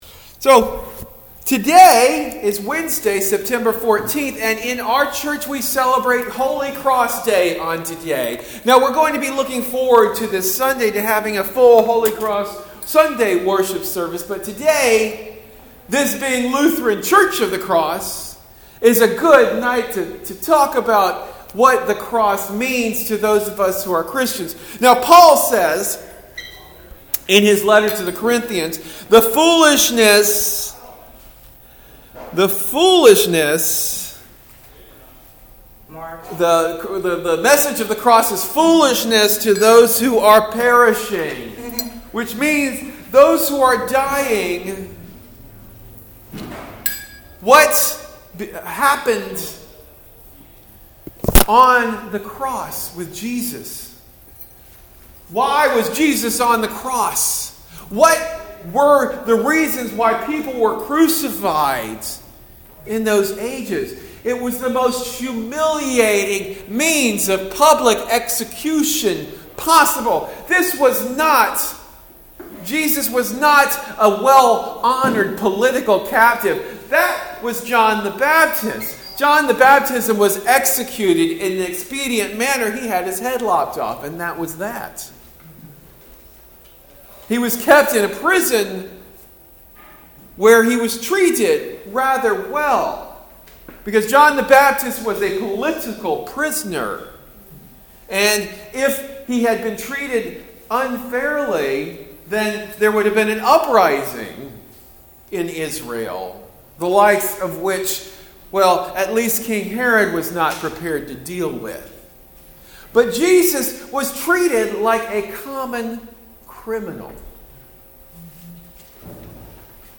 Sermon delivered at Lutheran Church of the Cross in Berkeley.